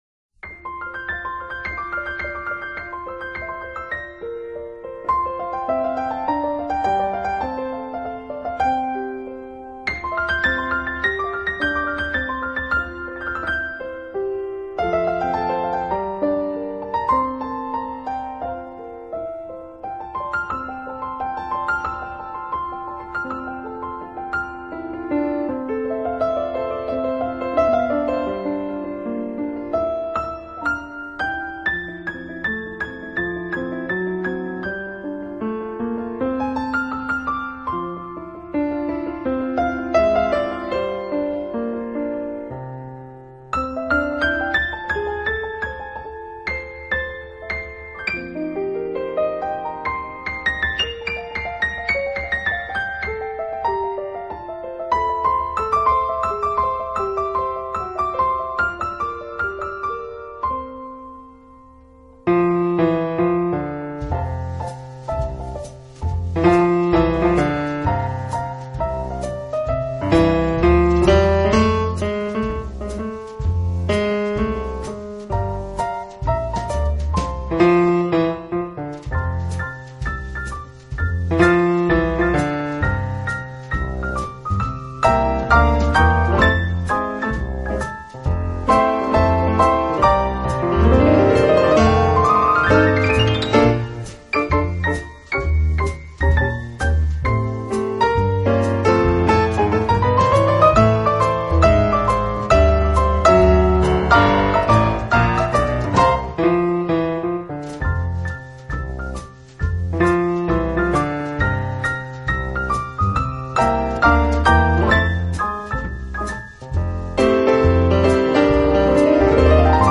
音乐风格：New Age/Jazz